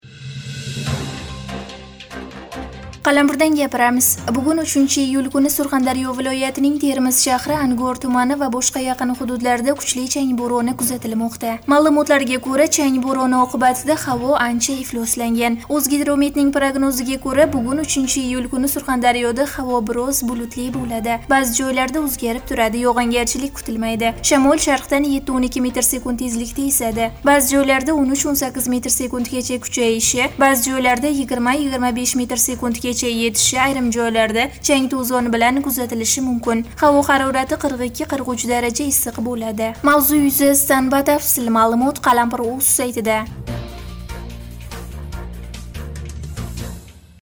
Бугун, 3 июль куни Сурхондарё вилоятининг Термиз шаҳри, Ангор тумани ва бошқа яқин ҳудудларда кучли чанг бўрони кузатилмоқда.